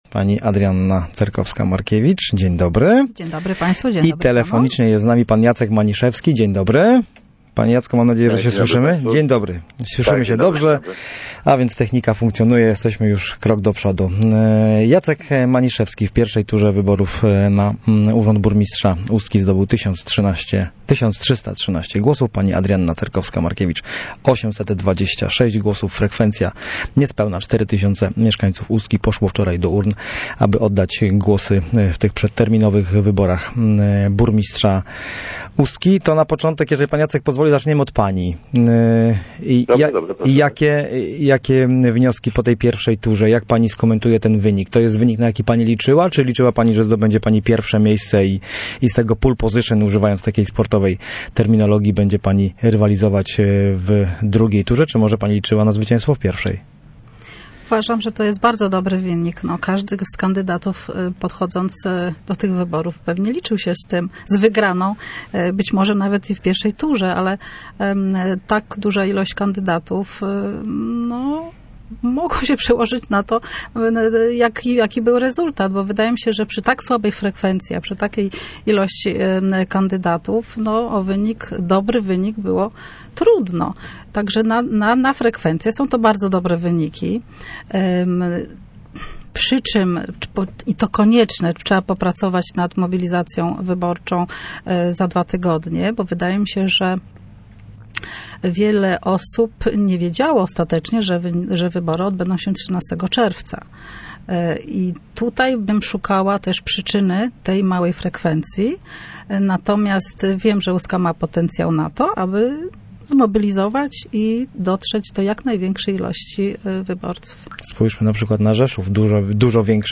Radia Gdańsk Studio Słupsk 102 FM. Całej rozmowy można posłuchać tu: